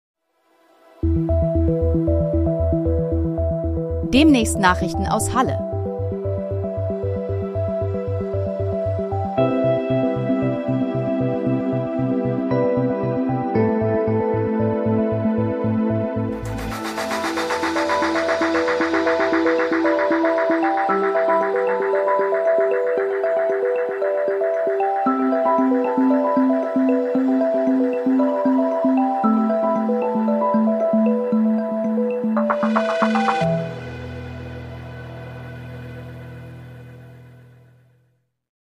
Heute in Halle: Trailer, erstellt mit KI-Unterstützung